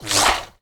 bullet_flyby_slow_01.wav